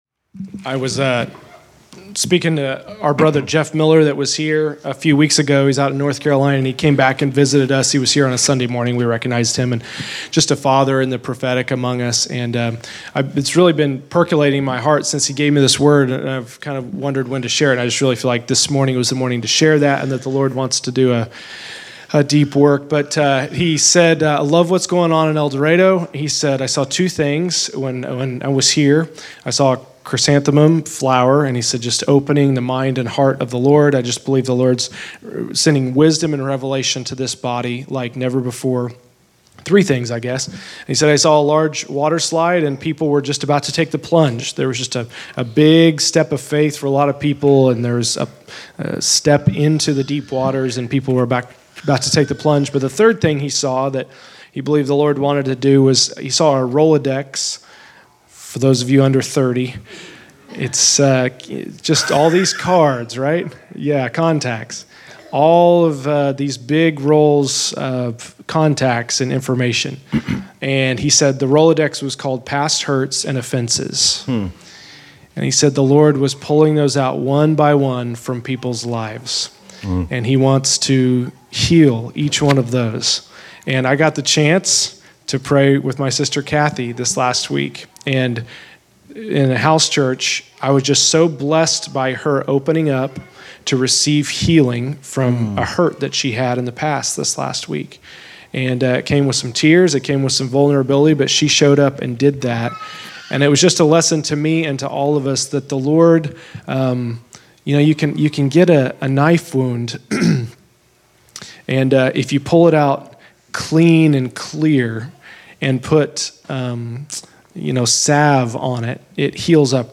Category: Exhortation